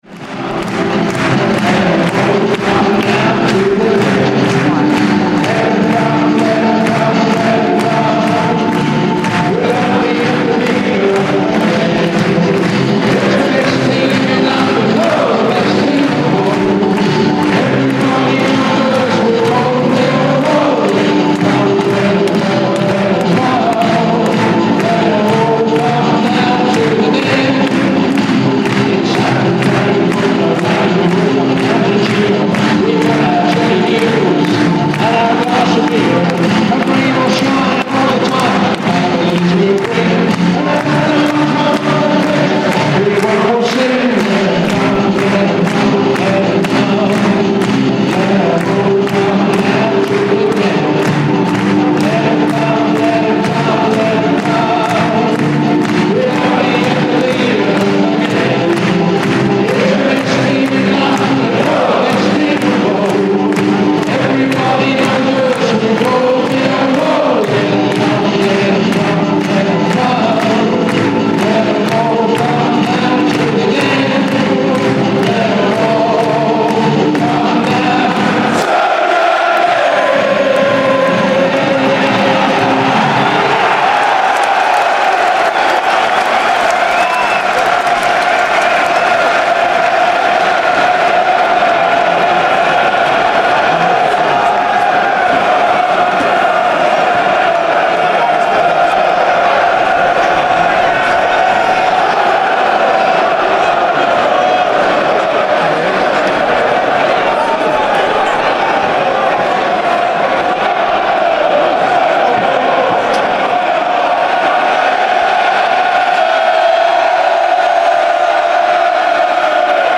A traditional introductory song at Millwall FC, followed by a minute of applause for Millwall fans who have passed away, and an unusual "siren" tone chant across the crowd, unique to this team's fans.